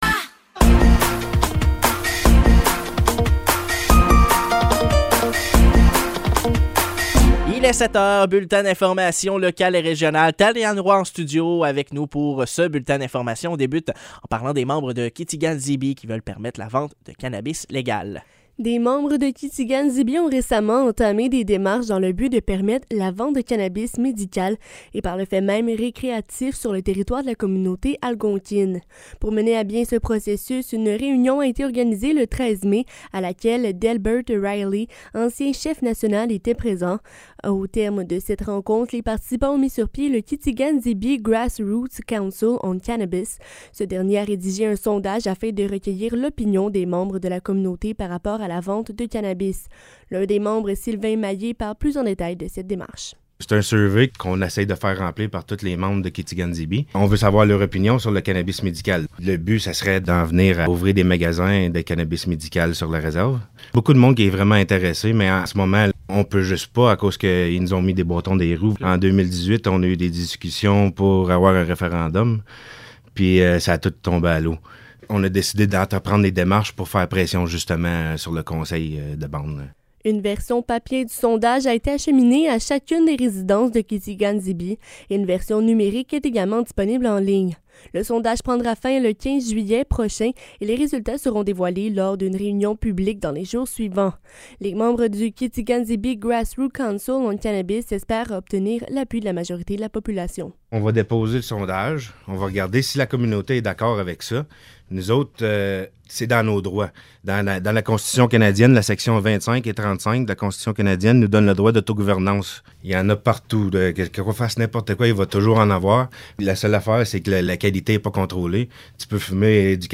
Nouvelles locales - 21 juin 2023 - 7 h